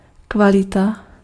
Ääntäminen
IPA : /ˈkwɒl.ɪ.ti/ IPA : /ˈkwɑl.ɪ.ti/